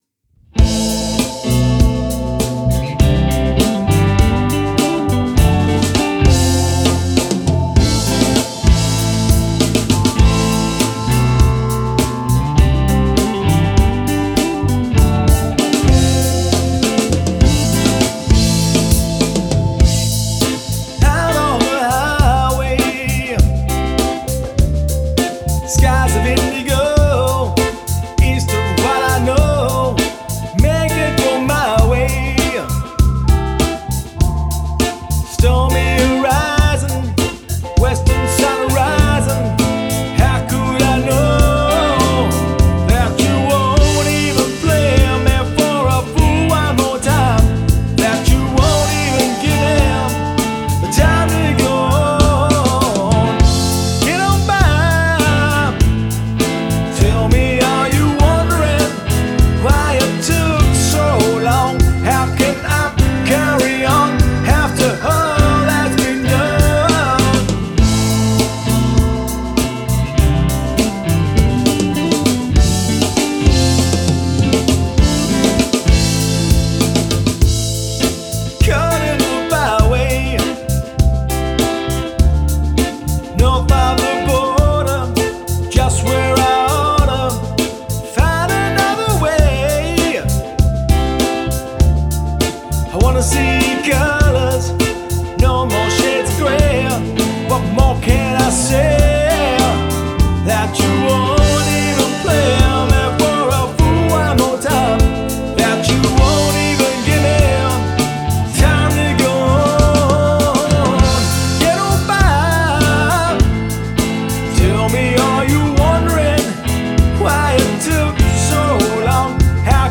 Guitars, Vocals & Bass
Hammond Organ, Wurlitzer & Fender Rhodes
blues-edged rock